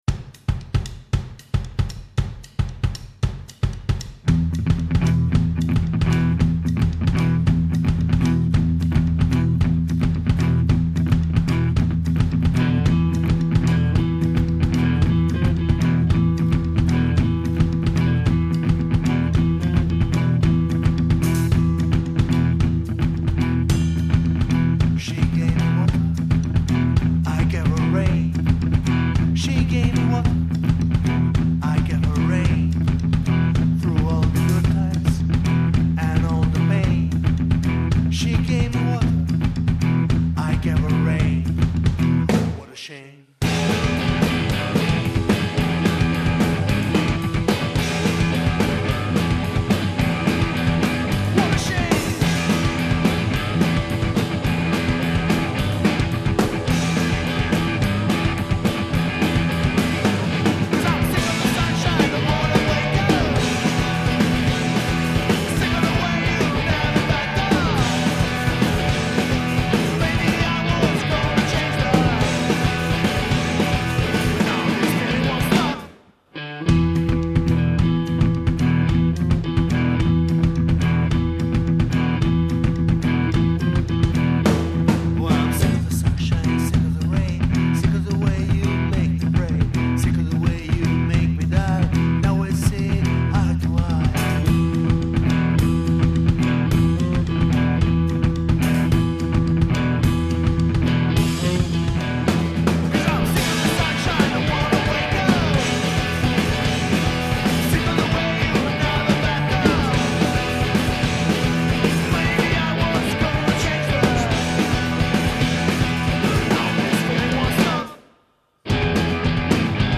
rock'n'roll
La band bolognese è stata così gentile da regalarci il demo